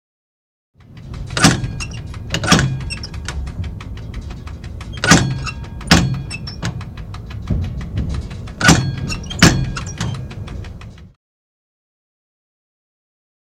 Bell ( Interior ); Tram Bell Tinges From Interior With Off Screen Traffic Sounds.